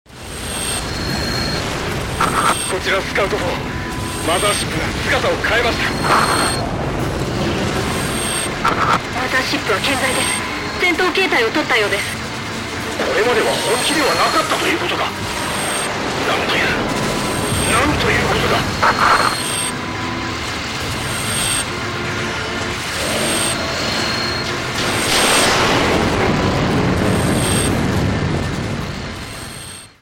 隊長ボイス（MP3）
隊長の絶望は（今回も）計り知れない。